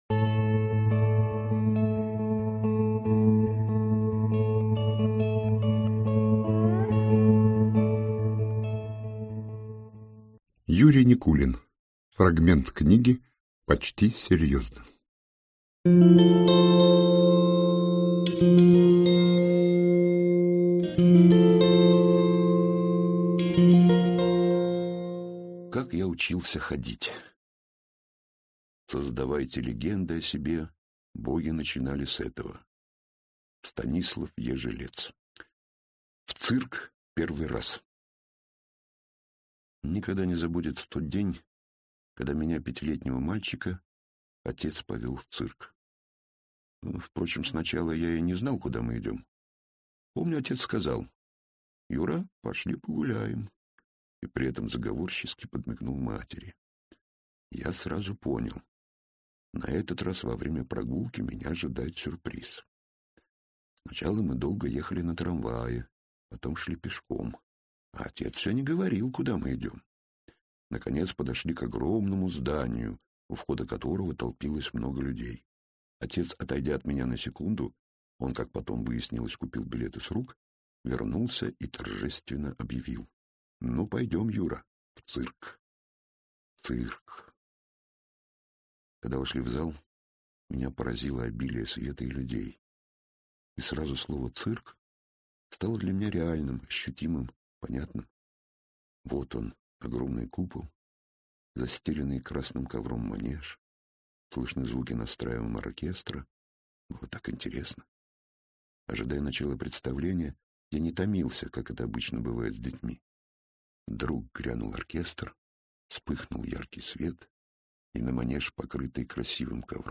Аудиокнига Как я учился ходить | Библиотека аудиокниг